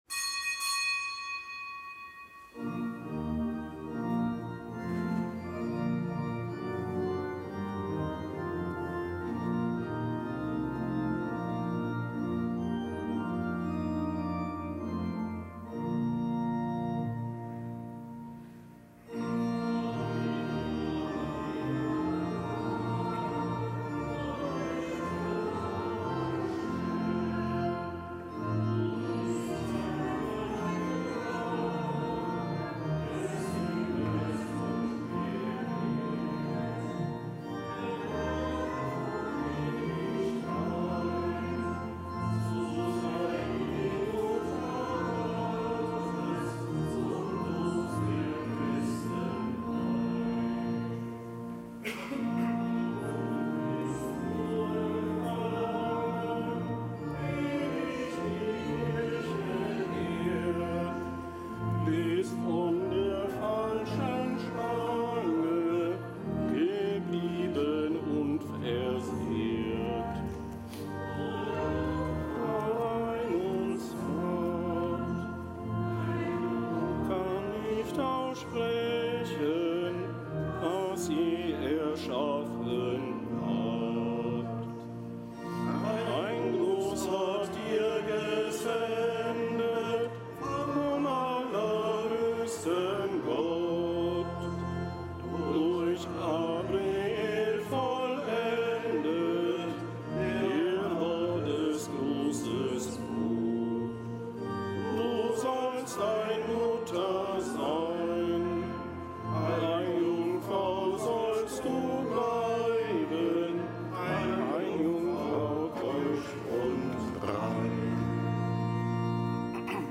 Kapitelsmesse im Kölner Dom zum Hochfest der ohne Erbsünde empfangenen Jungfrau und Gottesmutter Maria, Patronin der Erzdiözese Köln. Zelebrant: Weihbischof Dominikus Schwaderlapp.